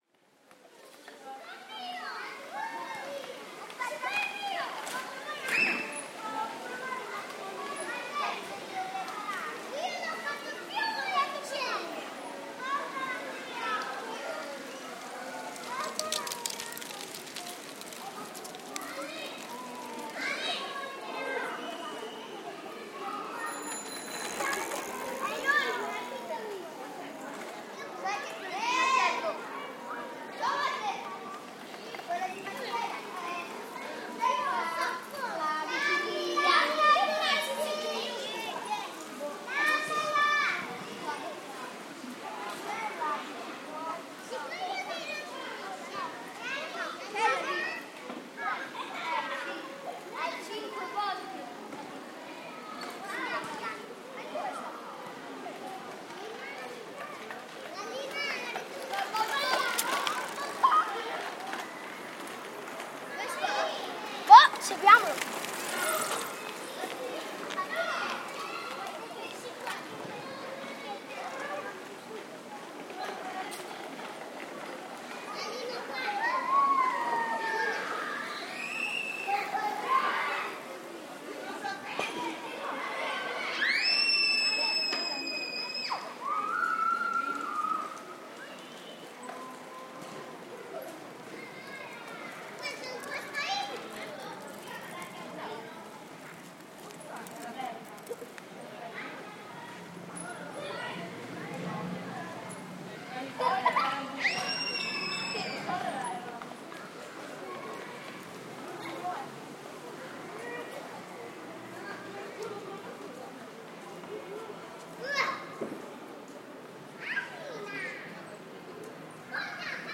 Giardini Maragliano in Florence, Italy.